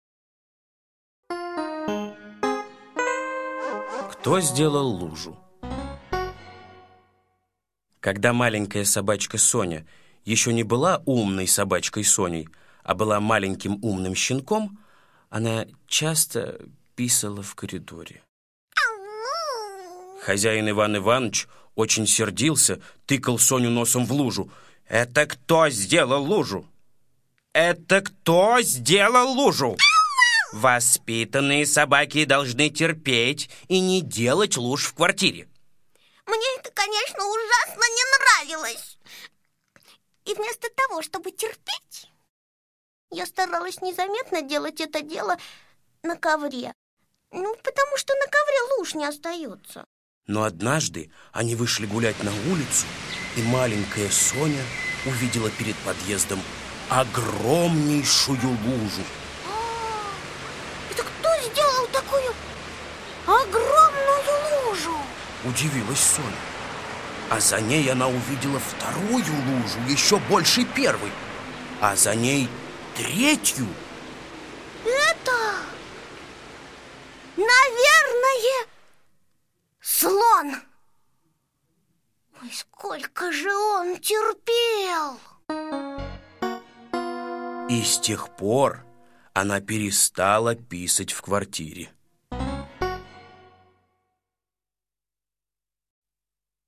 Кто сделал лужу - аудиосказка Усачева А.А. Когда собачка Соня была щенком, она писала в коридоре на пол, за что ее всегда ругал хозяин.